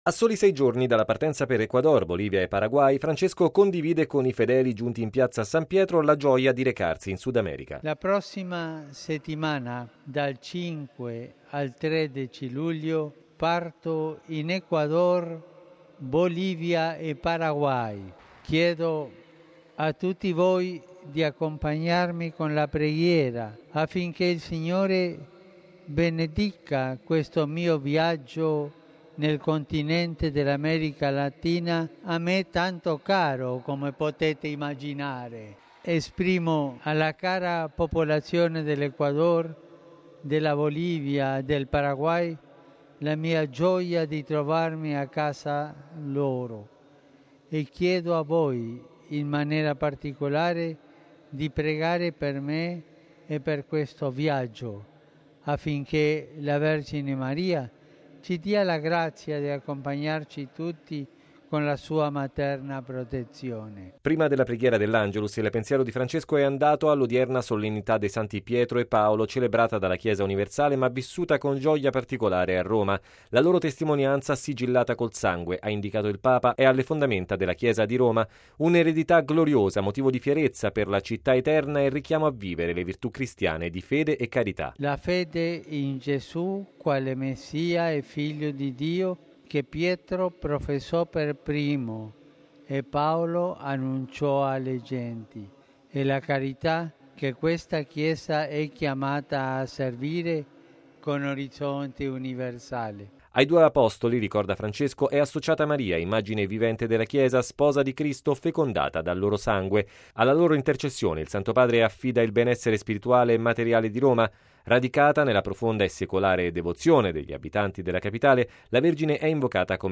Dopo la Messa il Papa si è affacciato alla finestra dello studio pontificio per l'Angelus e, davanti ai tanti fedeli presenti in un'assolata Piazza San Pietro, ha invitato a pregare per il suo prossimo viaggio in America Latina che lo porterà dal 5 al 13 luglio in Ecuador, Bolivia e Paraguay.